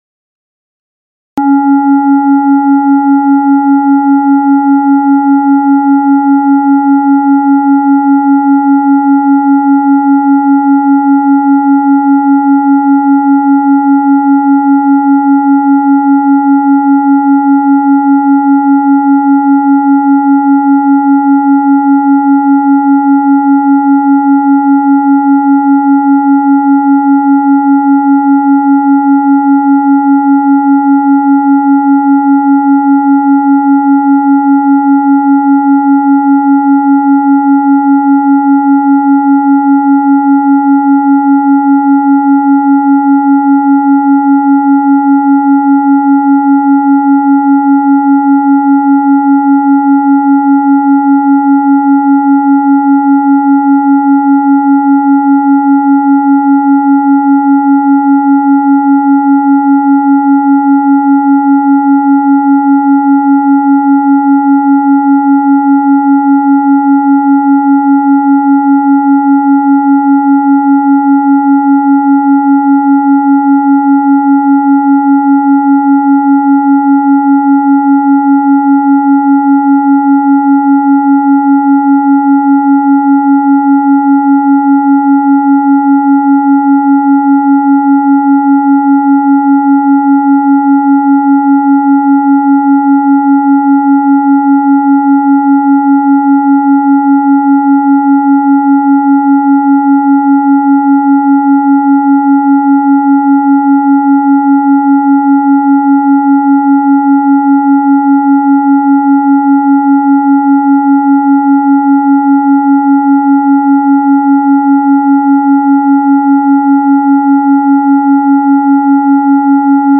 280 Hz + 555 Hz Multi-Frequency Tone Signal